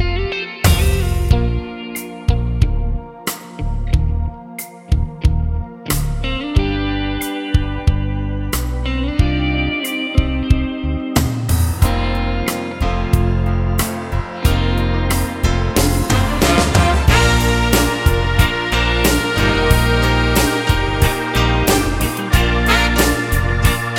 no Backing Vocals Soft Rock 4:37 Buy £1.50